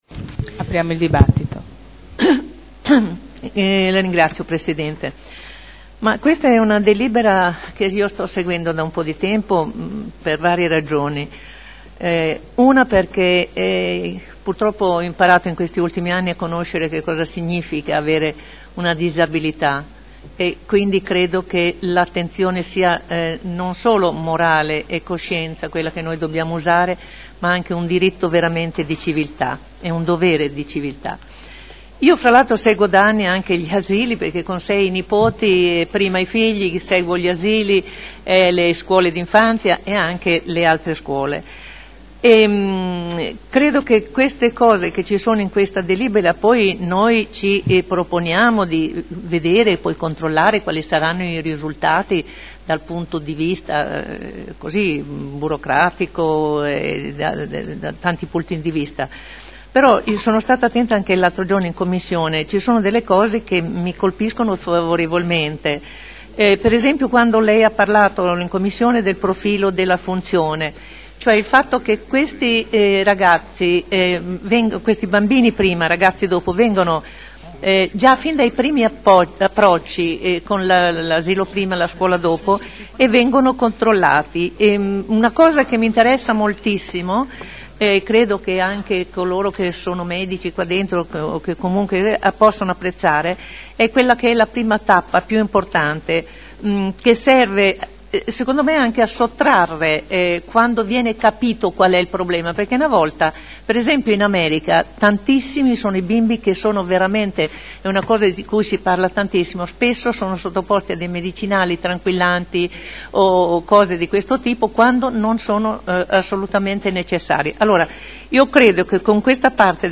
Olga Vecchi — Sito Audio Consiglio Comunale
Dibattito.